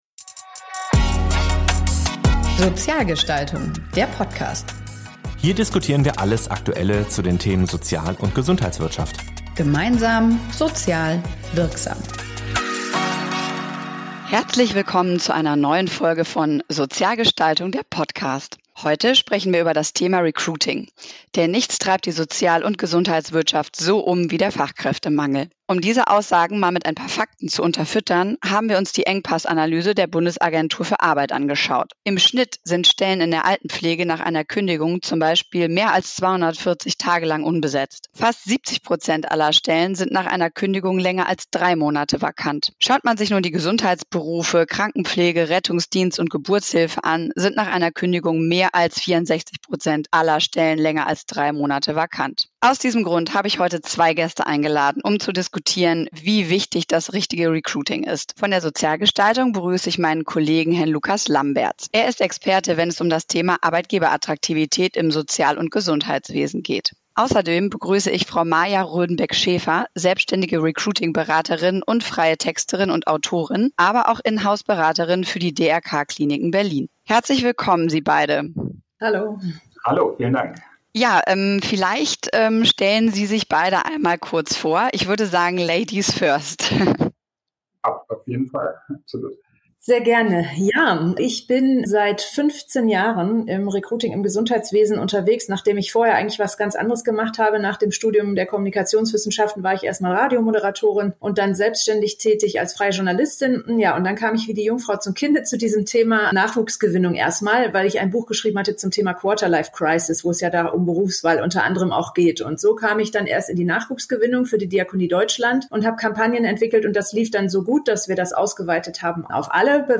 Gemeinsam diskutieren sie, wie modernes Recruiting heute aussehen muss und warum klassische Stellenanzeigen und Karriereseiten oft nicht mehr ausreichen.